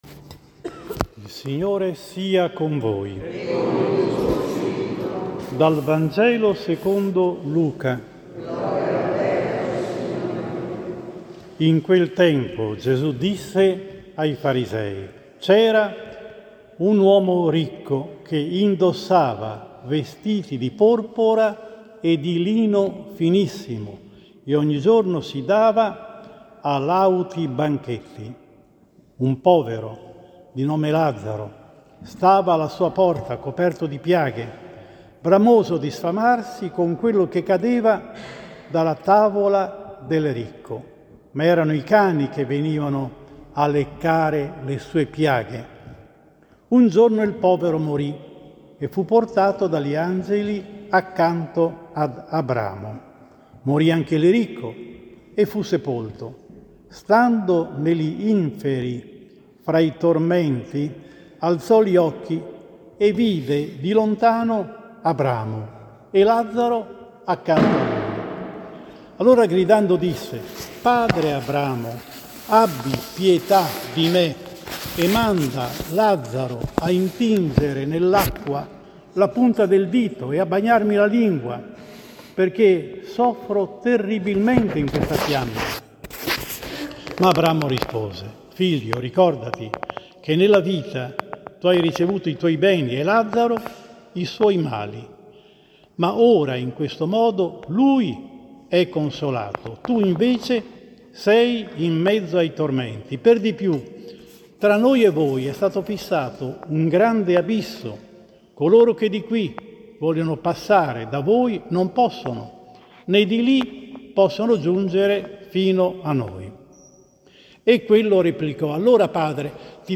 25 settembre 2022, Domenica XXVI anno C: omelia